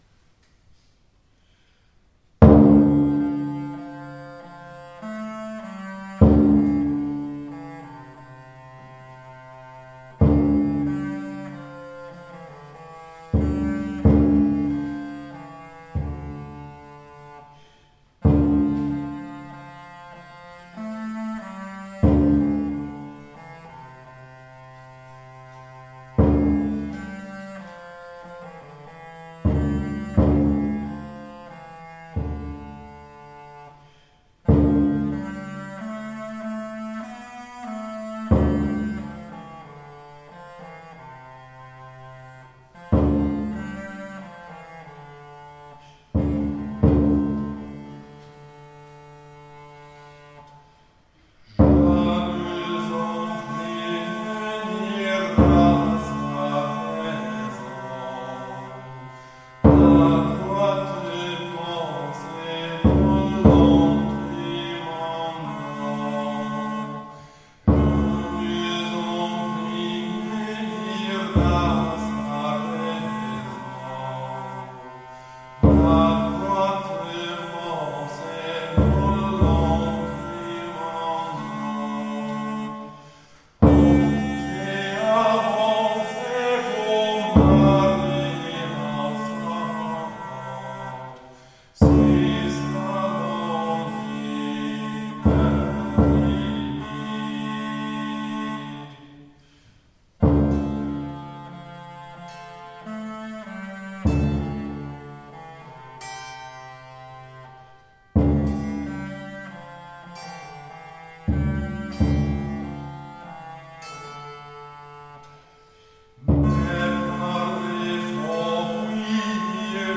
Die Hörbeispiele sind Live-Mitschnitte aus unterschiedlichen
Altkortholt, Tenorkortholt, Hackbrett, Trommel, Gesang